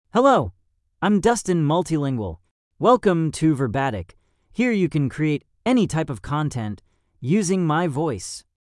MaleEnglish (United States)
Dustin Multilingual is a male AI voice for English (United States).
Voice sample
Listen to Dustin Multilingual's male English voice.